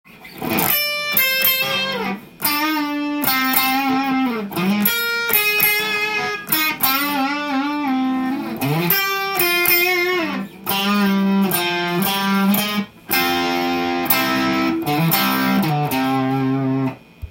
歪ませても良い音がします！ジャキジャキしていながら
低音も出るので弾いていて気持ちいですね！